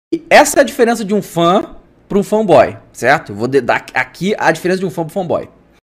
davy jones diferenca de um fa pra um fanboy Meme Sound Effect